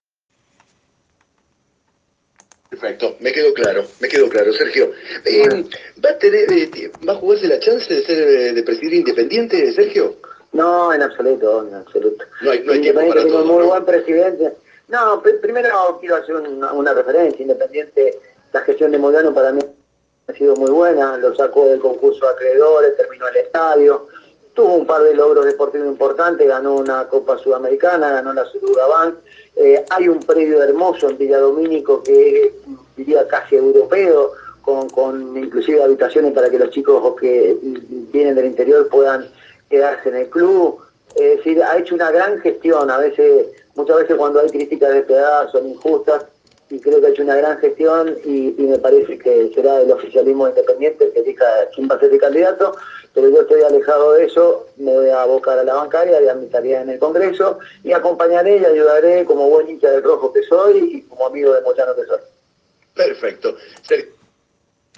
En diálogo con Radio Ensamble, Palazzo clausuró cualquier tipo de posibilidad sobre presentarse como candidato en las elecciones de Independiente.